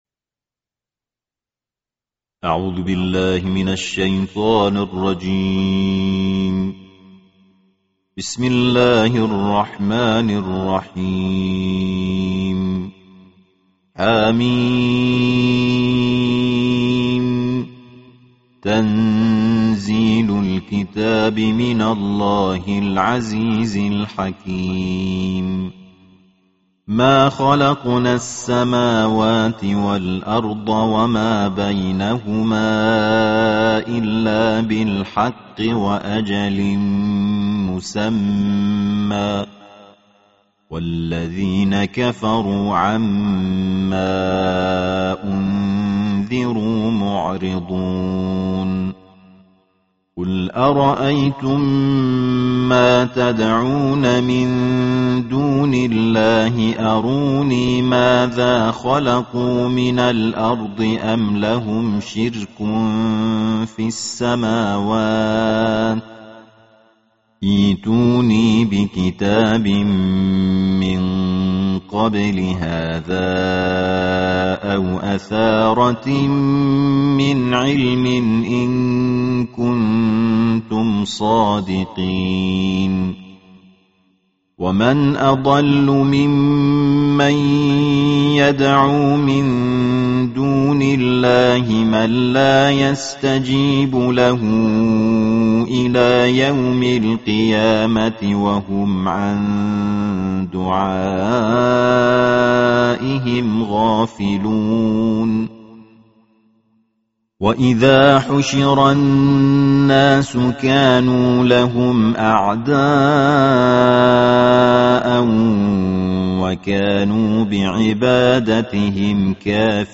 Tartil Juz Ke-26 Alquran